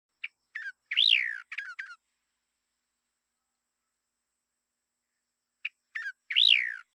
シロガシラ｜日本の鳥百科｜サントリーの愛鳥活動
「日本の鳥百科」シロガシラの紹介です（鳴き声あり）。ヒヨドリより一回り小型の、ヒヨドリの仲間。